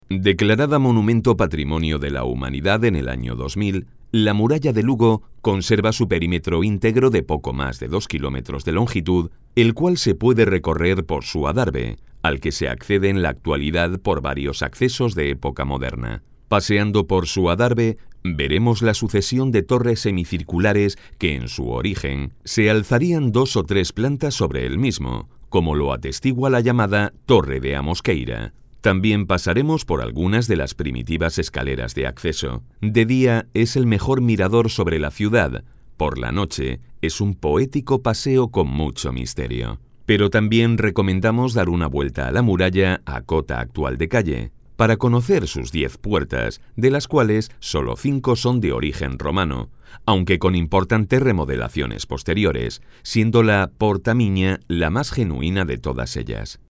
Voz directa para transmitir emociones
Sprechprobe: eLearning (Muttersprache):
Direct voice to convey emotions